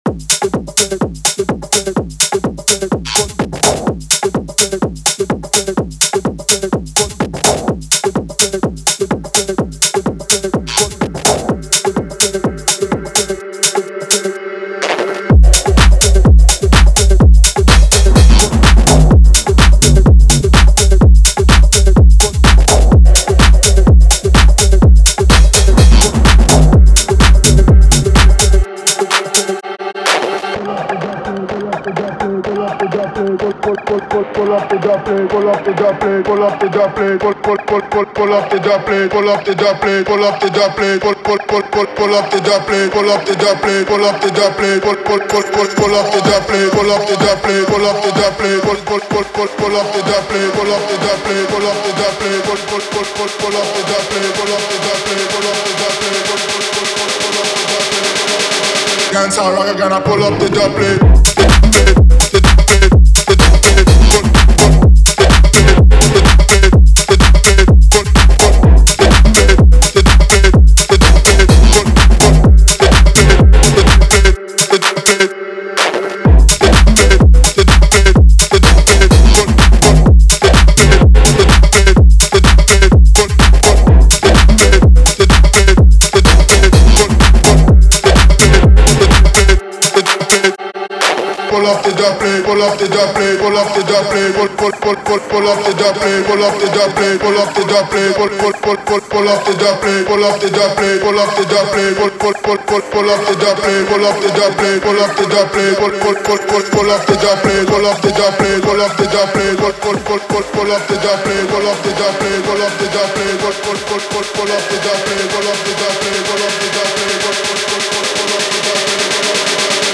• Жанр: Dance, House